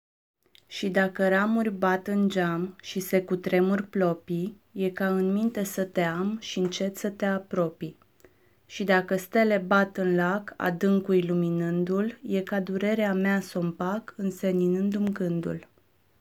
Here we have rhymes, nasal sound and a tender language, I think.